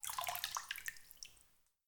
water-dabble-soft
Category 🌿 Nature
bath bathroom bathtub bubble burp click drain drip sound effect free sound royalty free Nature